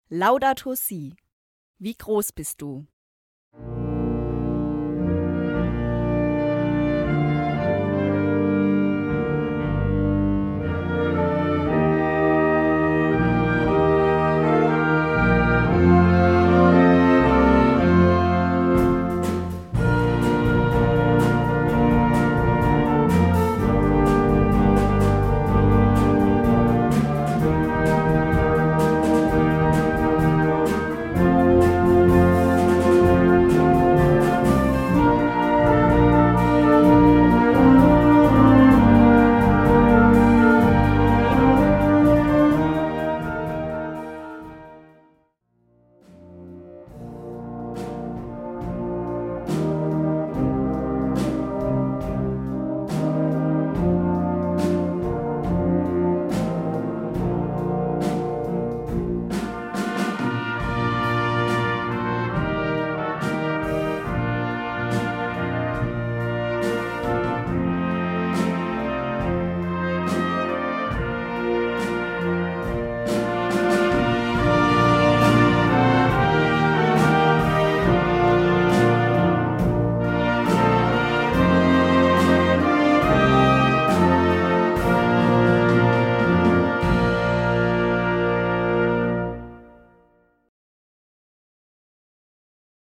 Gattung: Konzertantes Kirchenwerk
Besetzung: Blasorchester